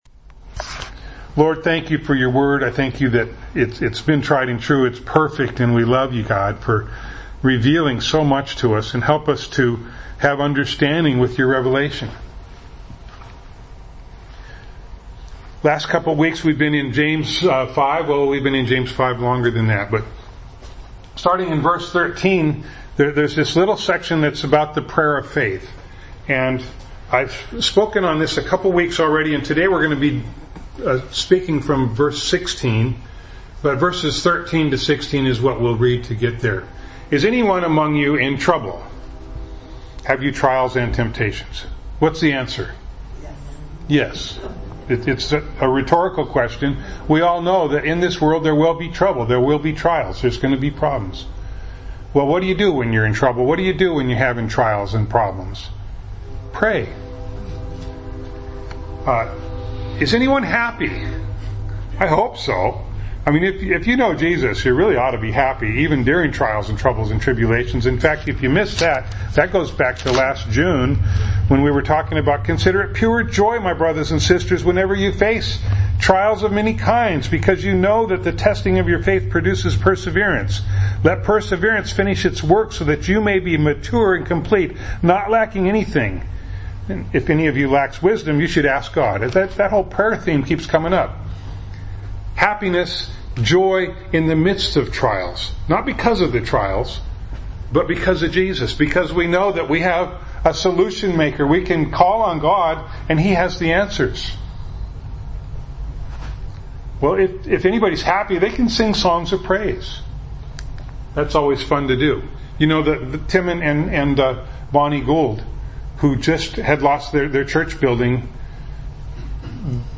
James 5:16 Service Type: Sunday Morning Bible Text